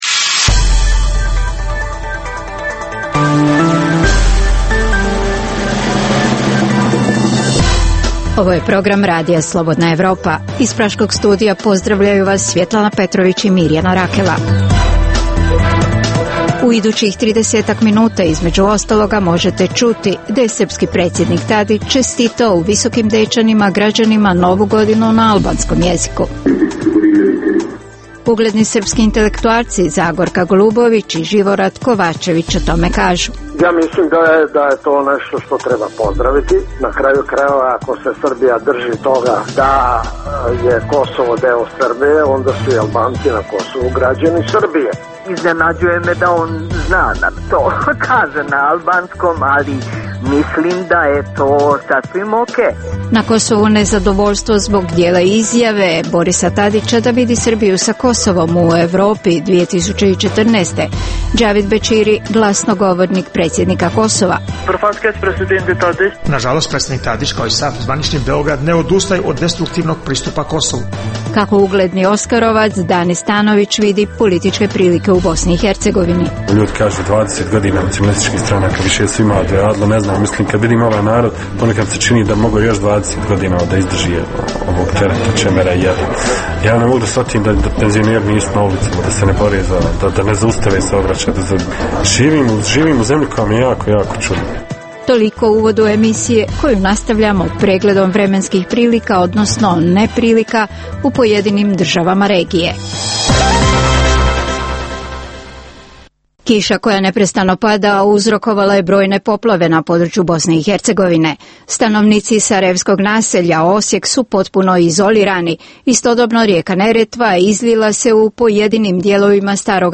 Reportaže iz svakodnevnog života ljudi su svakodnevno takođe sastavni dio “Dokumenata dana”.